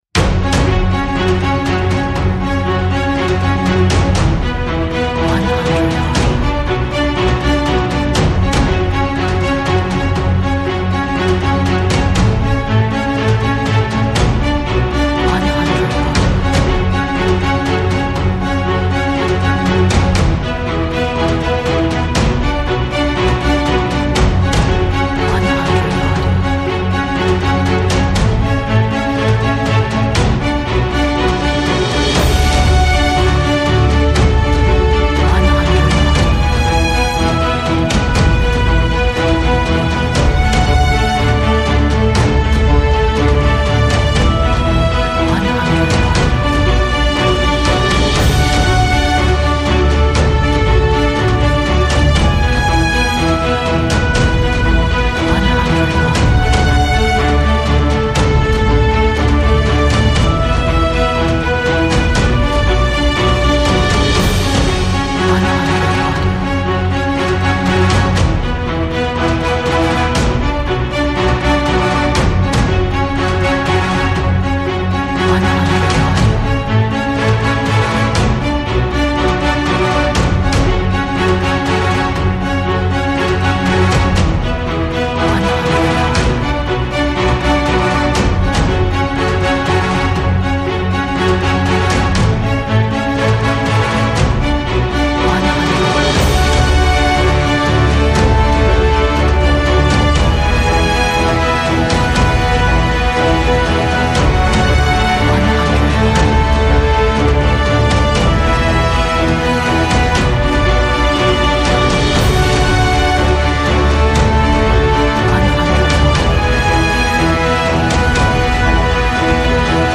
Orchestral, epic, cinematic music.